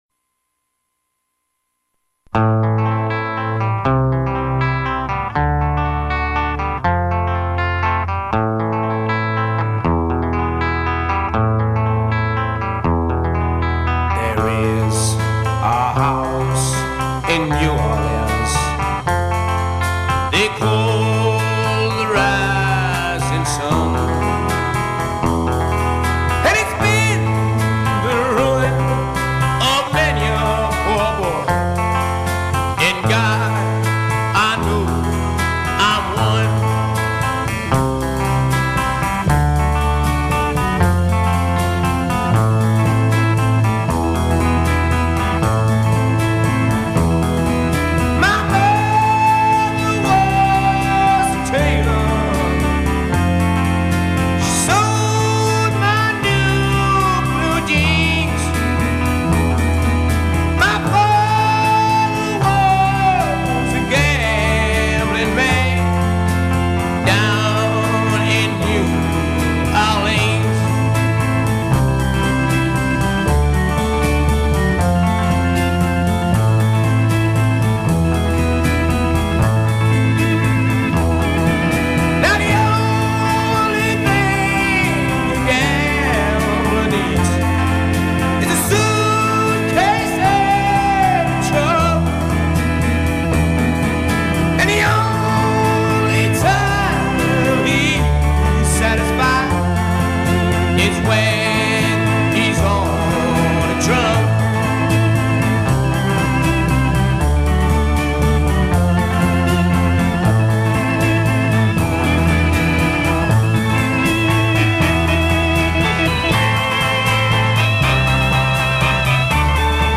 ballada folkowa znana z rockowego wykonania zespołu